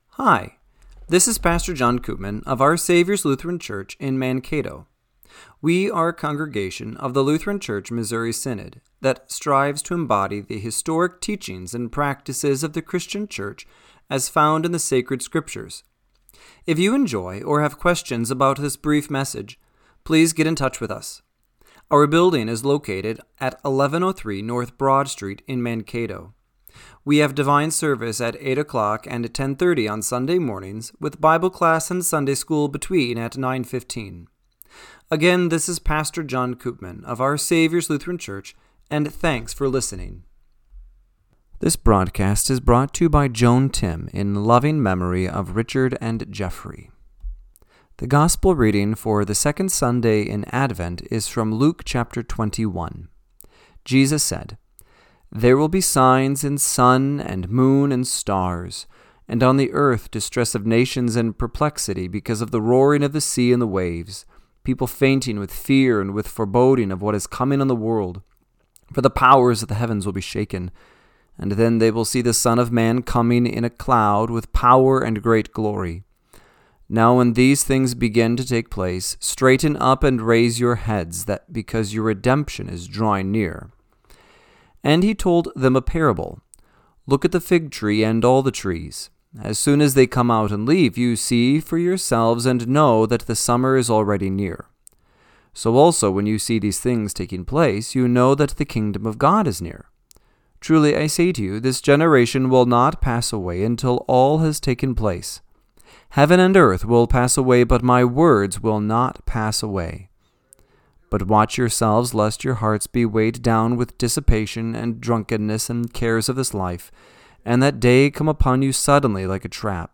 Radio-Matins-12-7-25.mp3